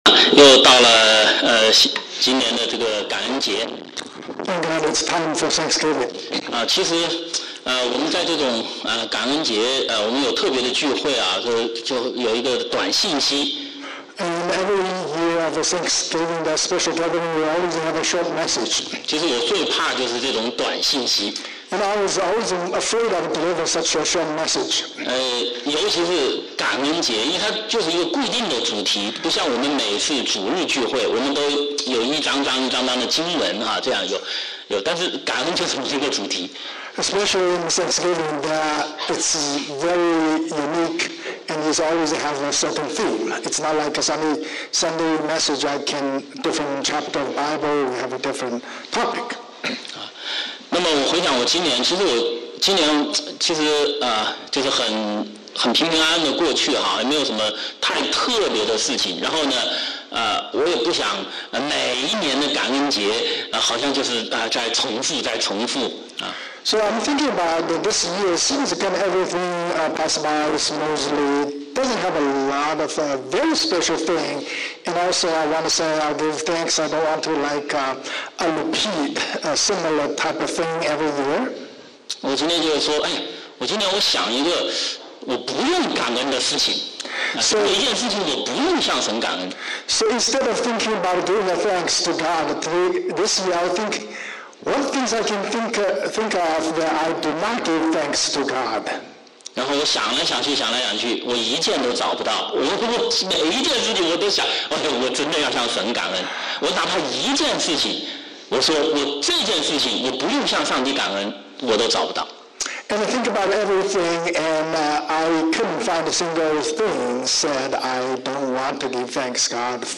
Thanksgiving 2024年感恩节特别聚会短讲
周五晚上查经讲道录音